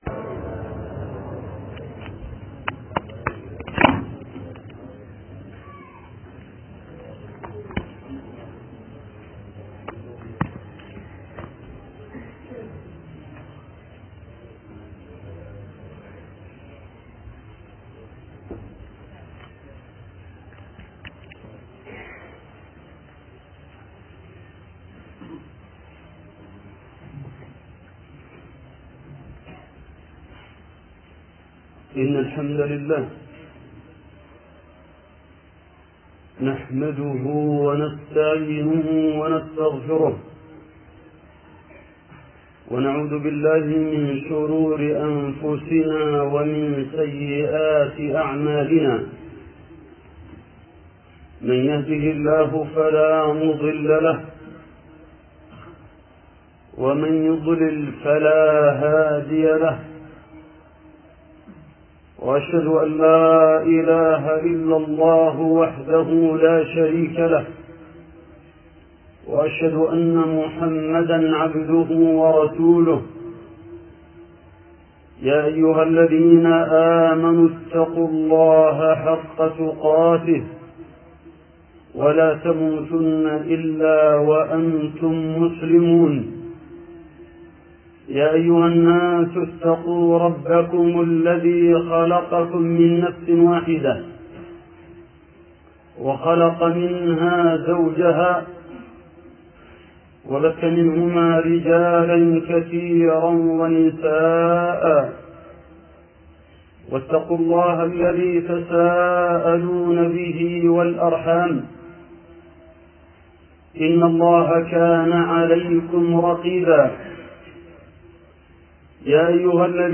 سلسلة خطب عن المرض 1 (الصبر على البلاء)